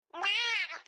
Cats Meowing Sound